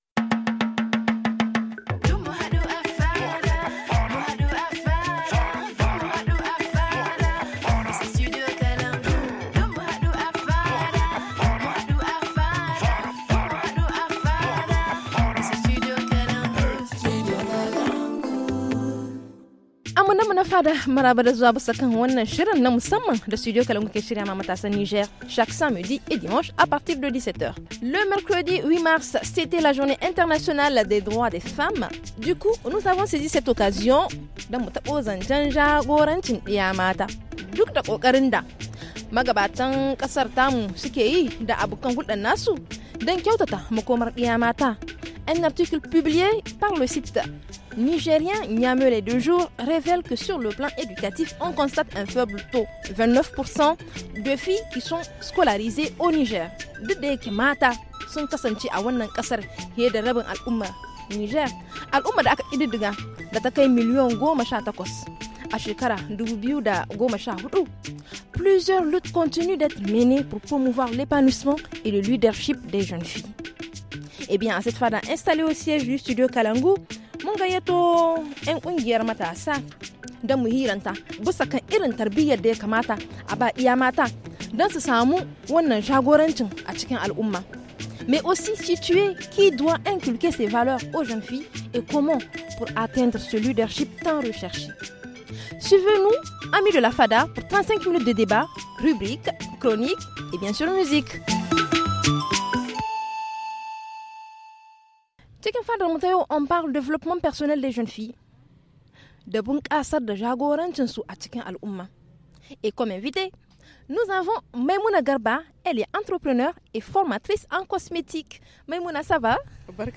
Et bien à cette fada installée au siège du Studio Kalangou nous avons convié des structures de jeunes afin de relever comment inculquer ces valeurs aux jeunes filles et comment pour atteindre ce leadership tant rechercher ?